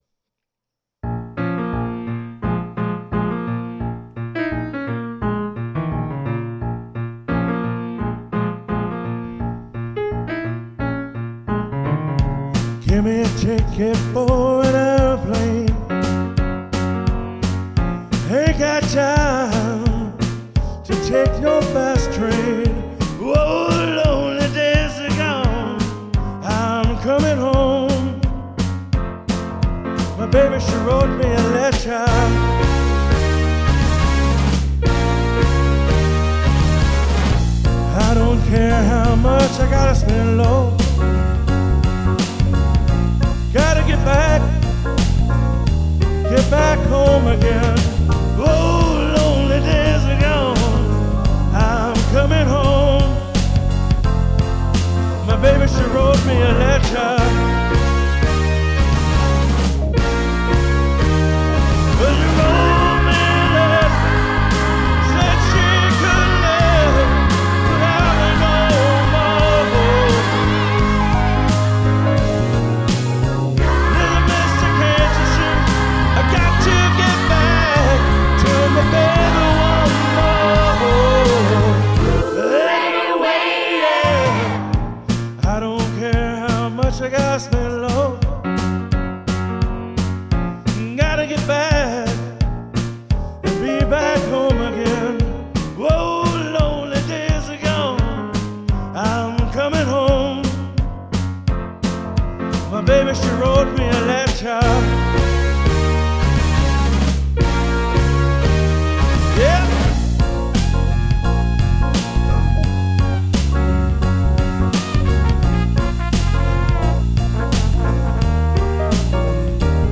Karaoke Songs